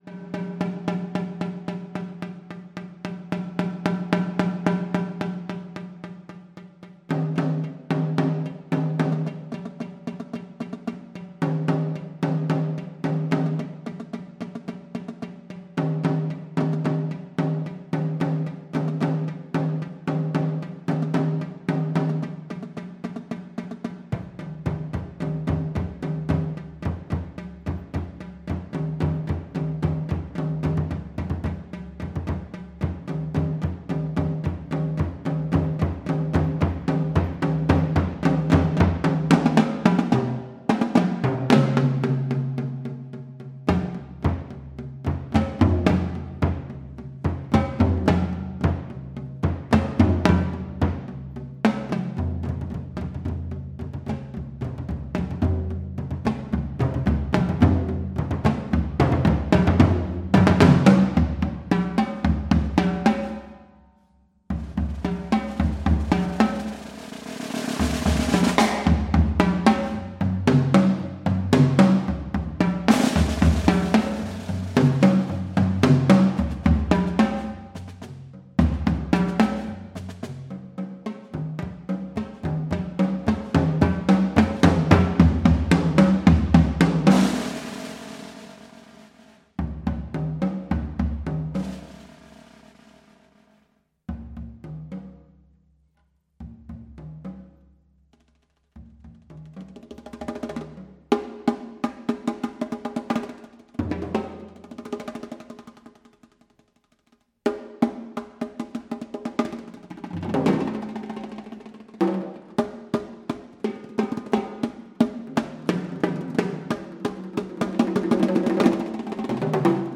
documenting never before heard chamber works